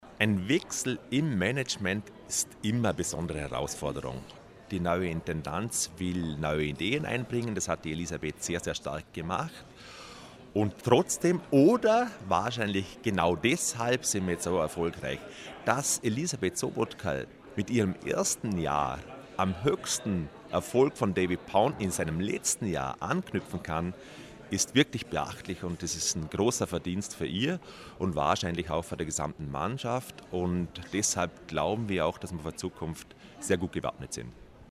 Pressekonferenz "Endspurt" 2015 O-Ton